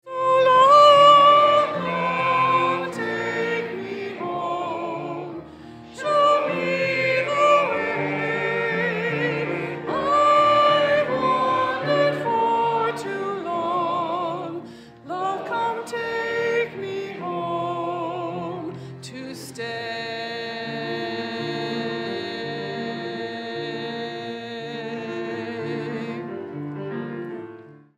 It has been adapted to be sung as a hymn.